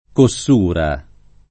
Cossura [ ko SS2 ra ]